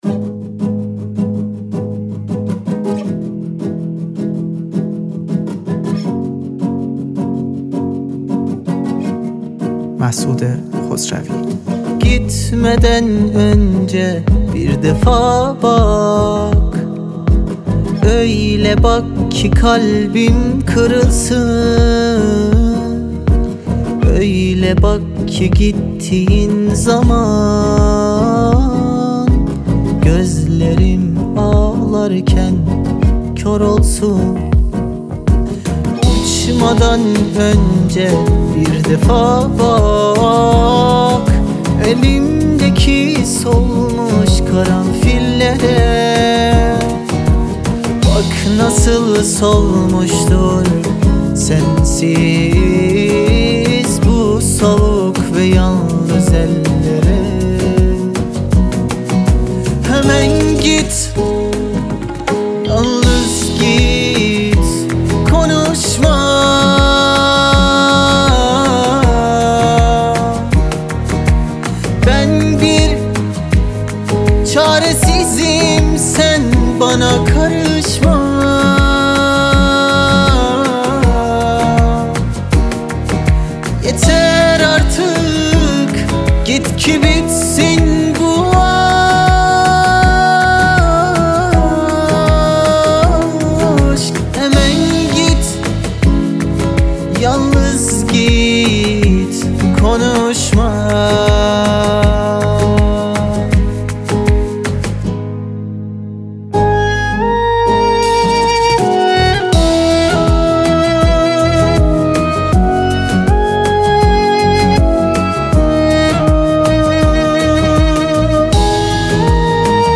آهنگ آذری و ترکی